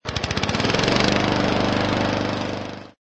carWalking.ogg